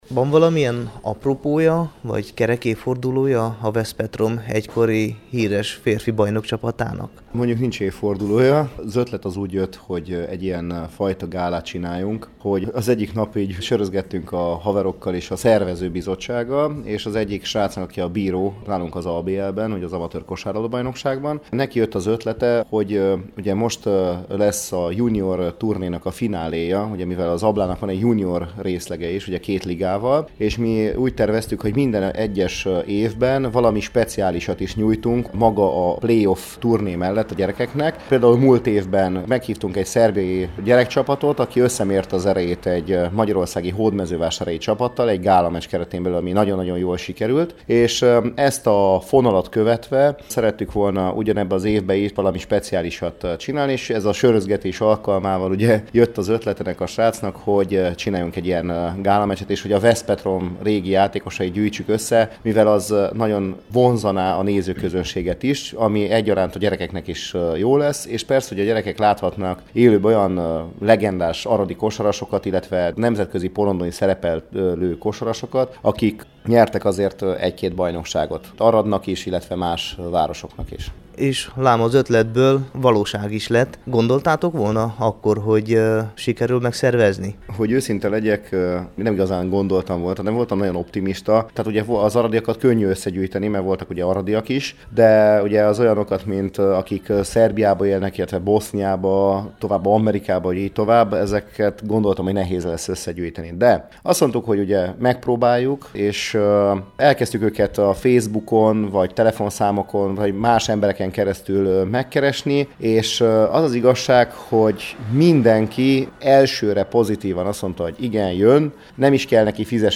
a Temesvári Rádióban